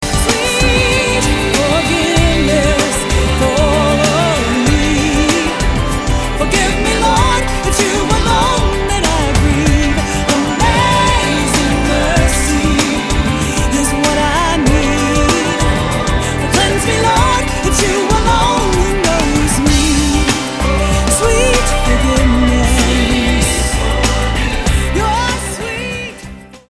STYLE: Pop
gospel-infused